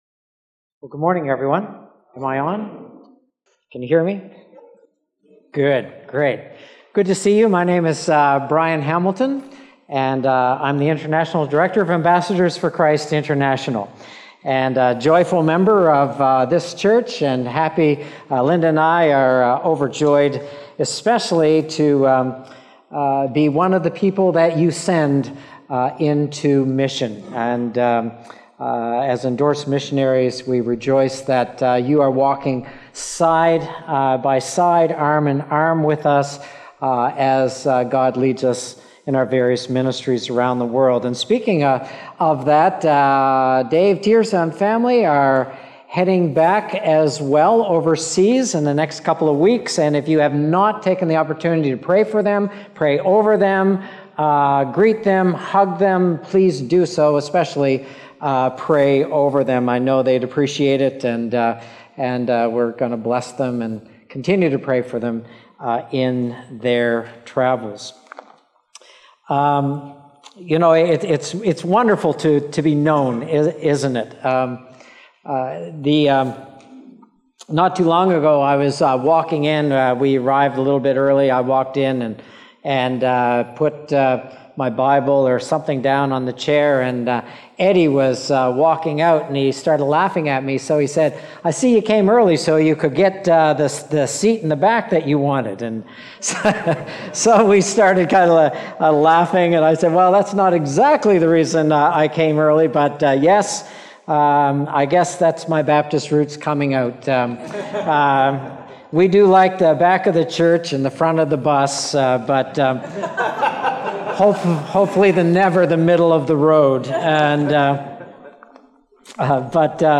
Sermon Detail
June_30th_Sermon_Audio.mp3